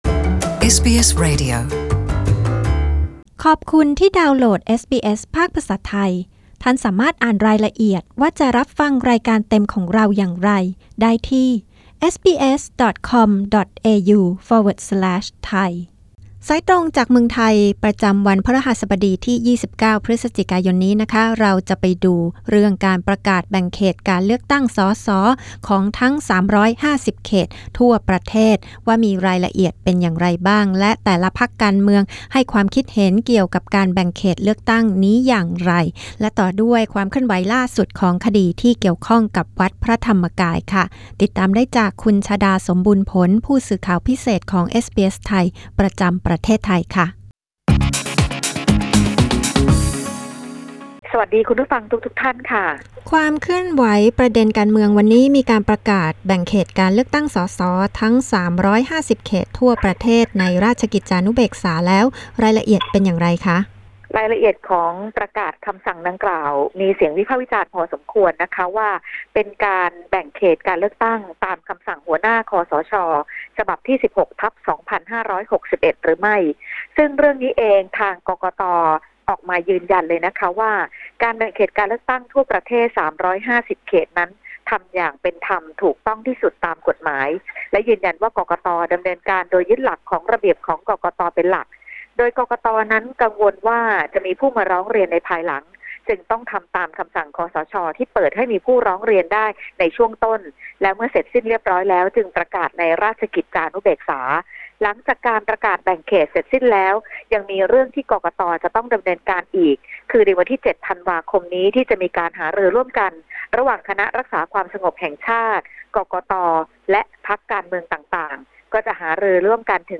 รายงานข่าวสายตรงจากเมืองไทย 29 พ.ย.